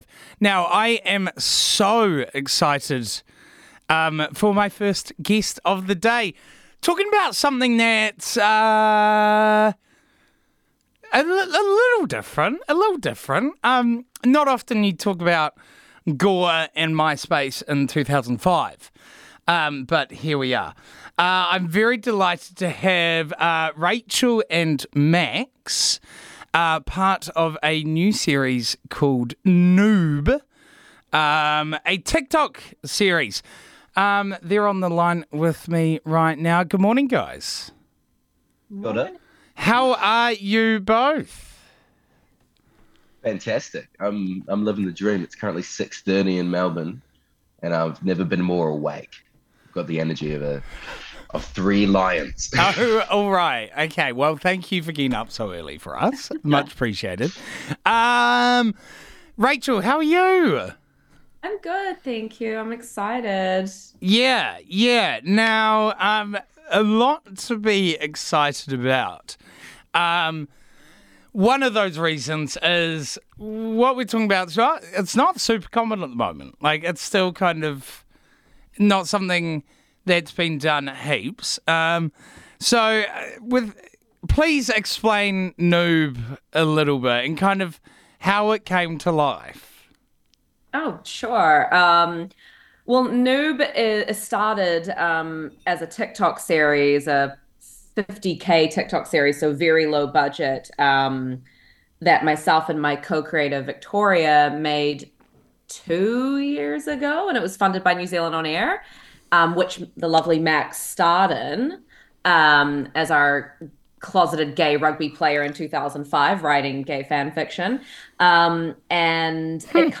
had a chat on Burnt Breakfast all about it!